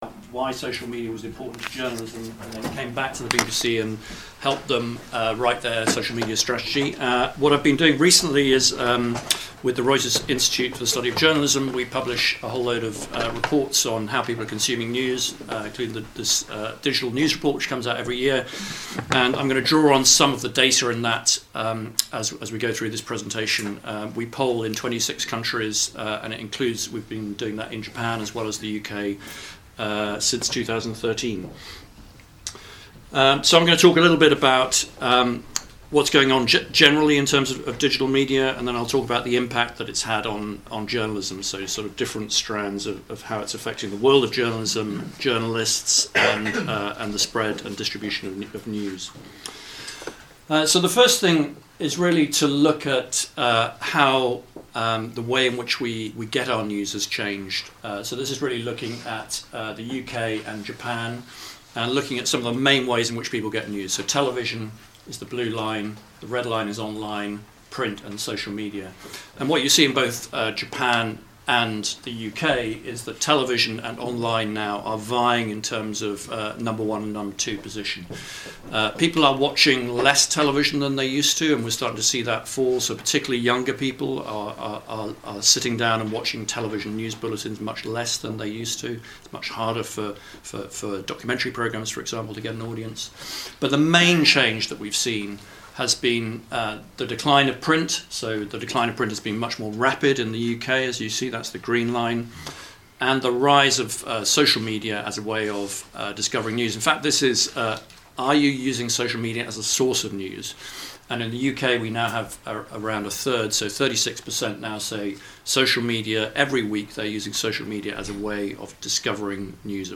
Event audio